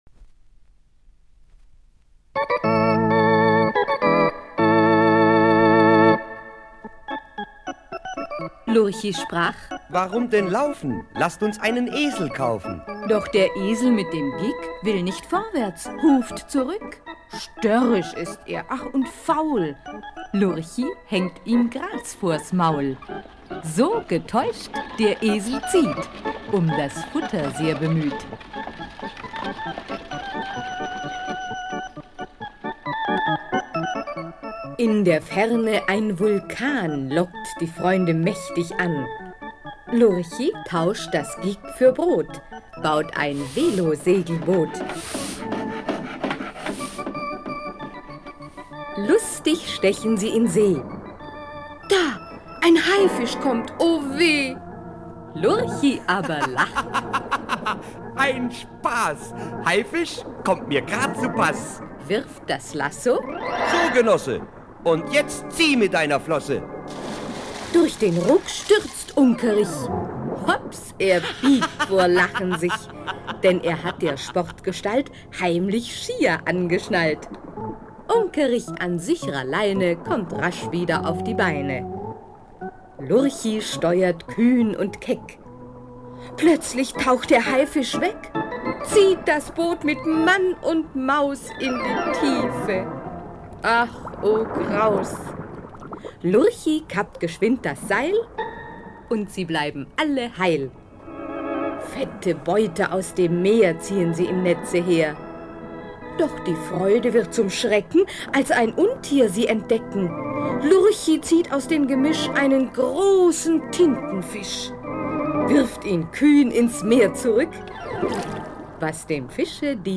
Lurchis Hörspiele
Lurchis Schallplatten